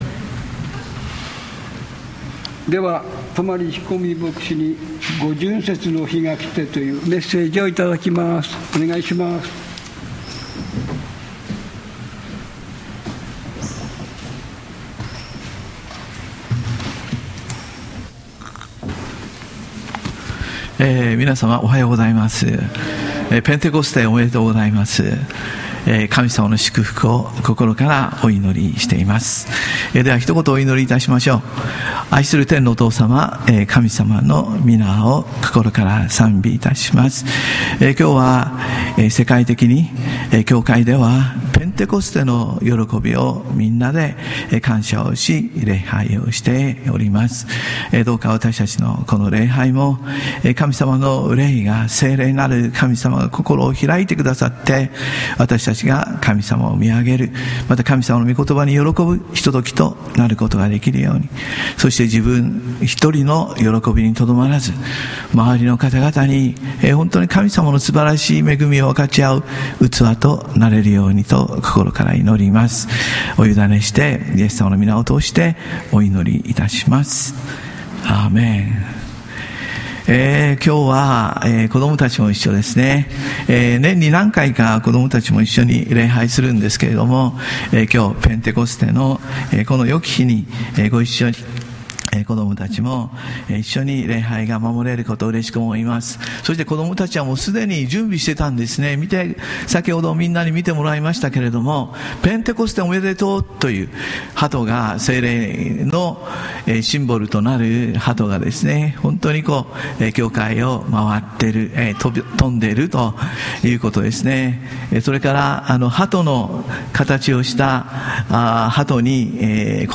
2022年6月5日ペンテコステ記念礼拝「五旬節の日が来て」